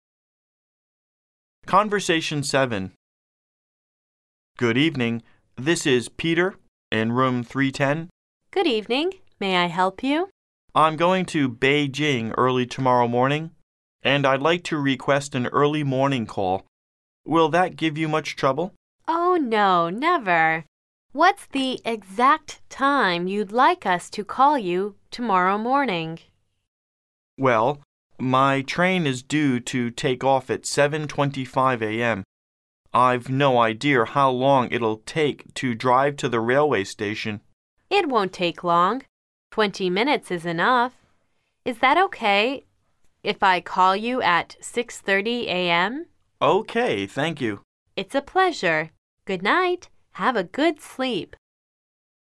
Conversation 7